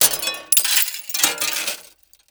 GLASS_Window_Break_12_mono.wav